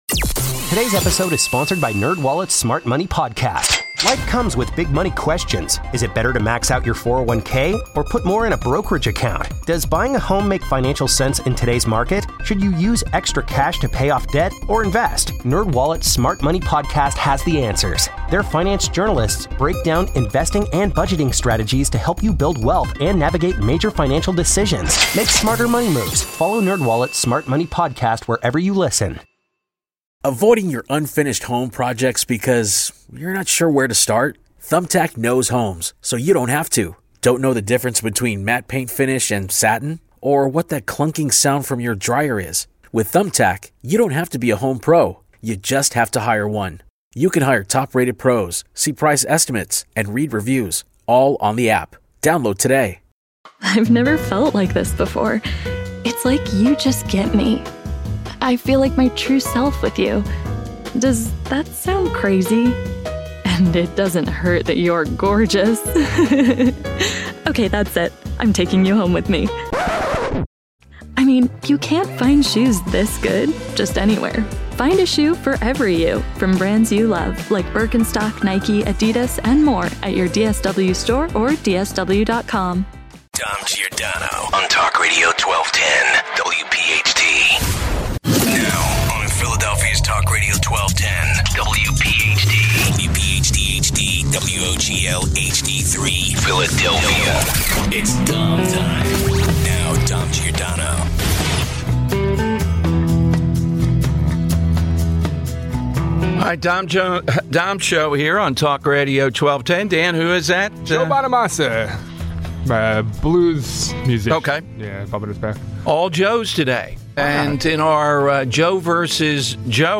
How hard will it be for RFK Jr. to get people to trust our public health services again? 140 - Will the media tone back their rhetoric after the ABC/Trump lawsuit settlement? Scott Jennings discusses the matter. 150